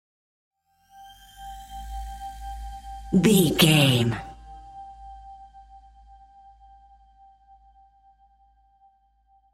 In-crescendo
Aeolian/Minor
scary
ominous
dark
suspense
eerie
Horror Synths
Scary Piano
Scary Strings